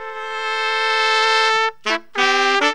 HORN RIFF 3.wav